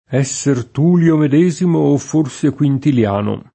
Tullio [t2llLo] pers. m. — es.: Servio Tullio sesto re di Roma (sec. VI a. C.); Marco Tullio Cicerone (106-43 a. C.), per i nostri antichi Tullio senz’altro — ant. Tulio [t2lLo]: esser Tulio medesimo o forse Quintiliano [